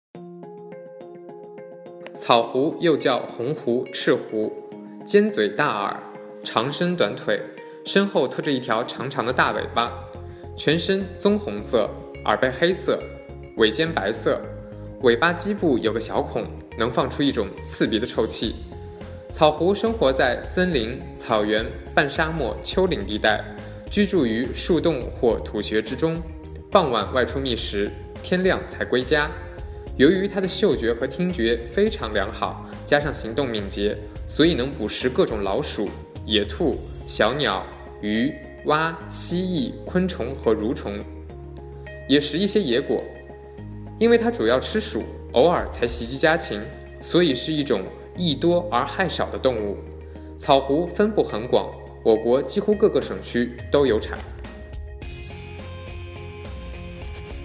草狐----- 手机用户 解说词: 草狐，又叫红狐、赤狐。